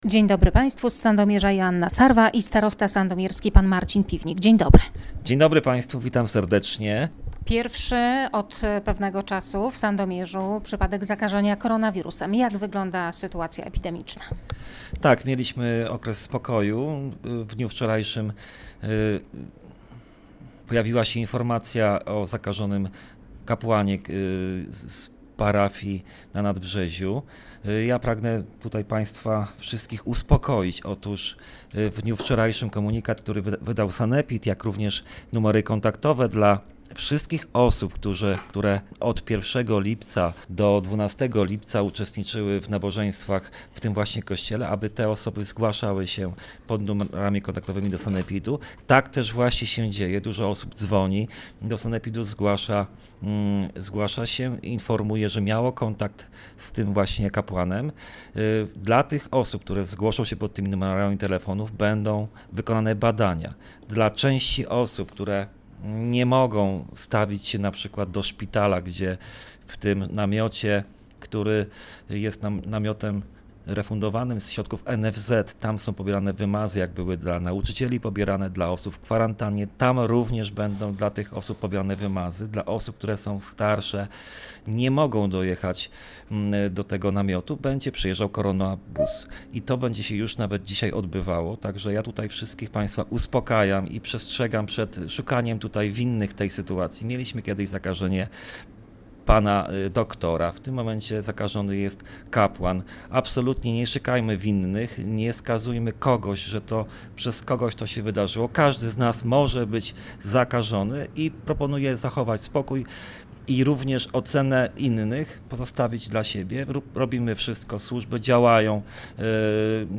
– Sytuacja jest opanowana – mówi starosta Marcin Piwnik.